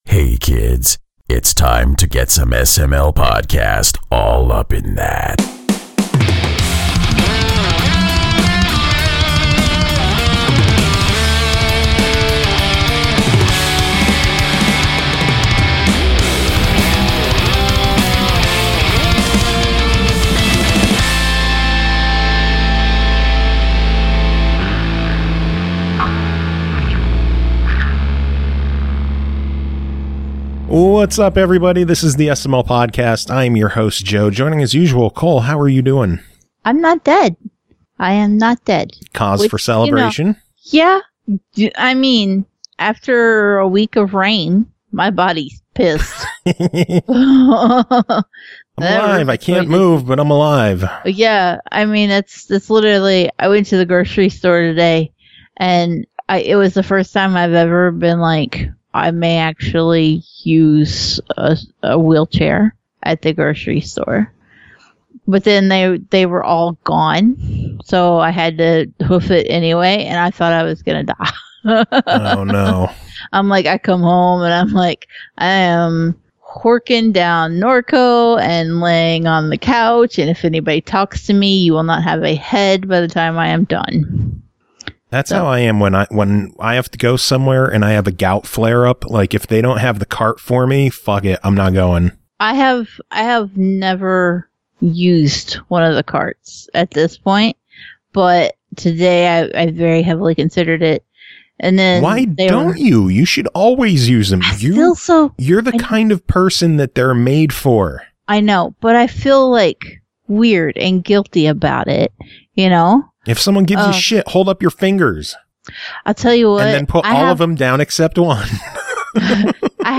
We end the show with the always awesome Viking Guitar Live!